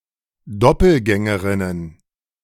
In German, there is also a feminine form, Doppelgängerin (plural Doppelgängerinnen تـُنطق [ˈdɔpl̩ˌɡɛŋəʁɪnən]
De-Doppelgängerinnen.ogg